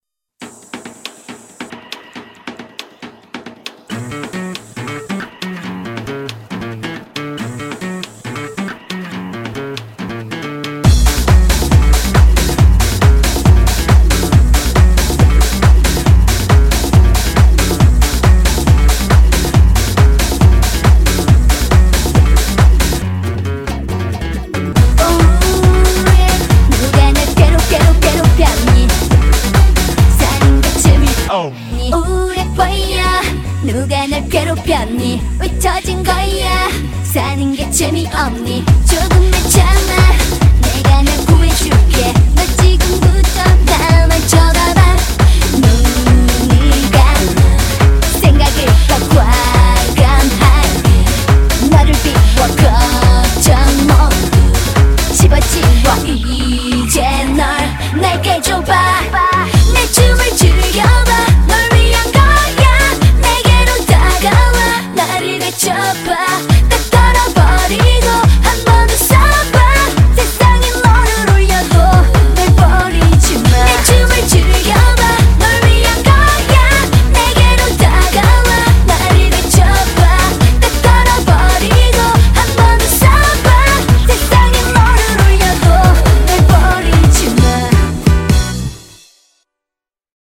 BPM138--1
Audio QualityPerfect (High Quality)